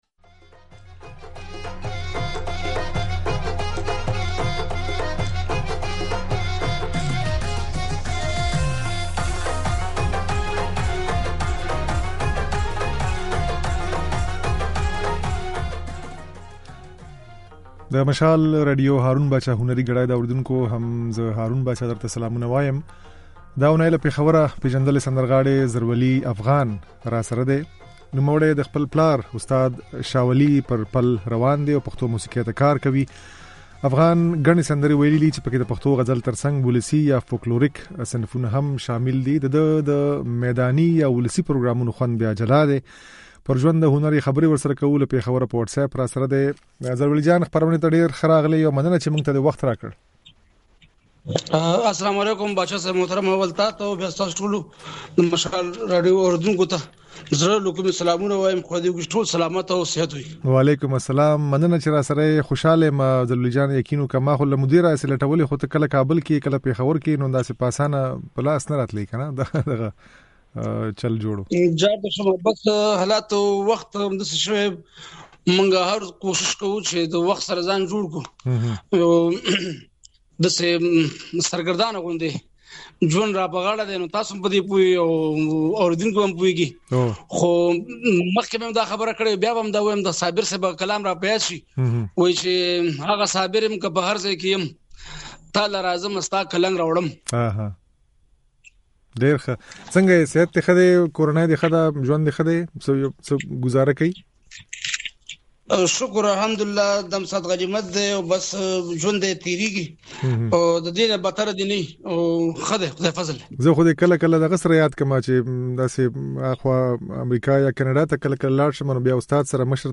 خبرې او ځينې سندرې يې اورېدای شئ.